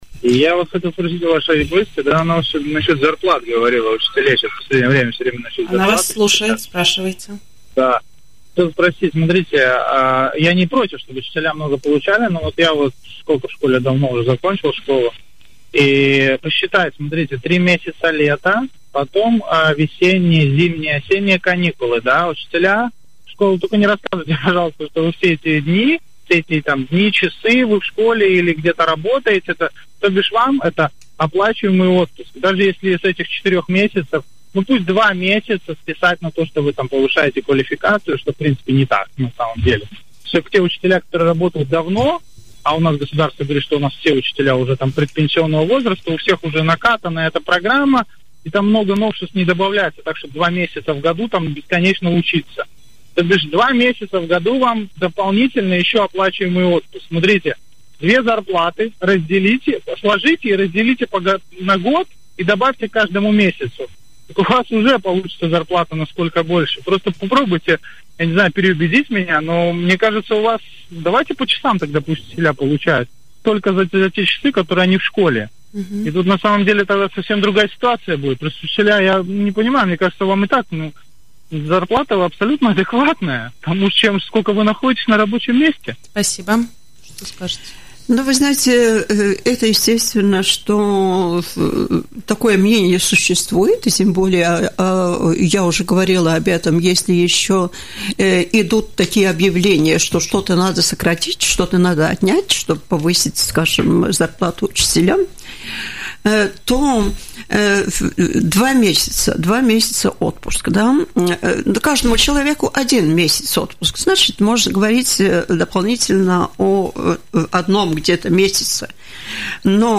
В Латвии учителя не должны жаловаться на свои зарплаты, так как они соответствуют тому времени, которое педагоги проводят в школе, сказал слушатель в эфире радио Baltkom, обращаясь к главе Комитета образования, культуры и спорта Рижской думы Эйжении Алдермане.